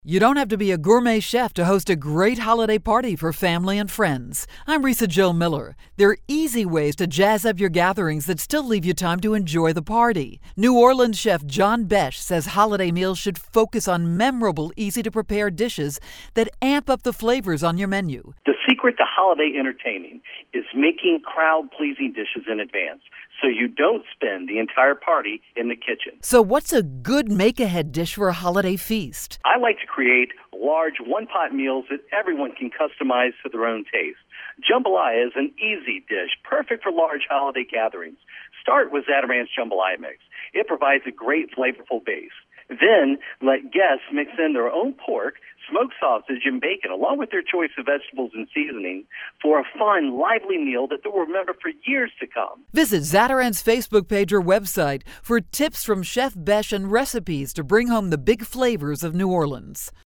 December 1, 2011Posted in: Audio News Release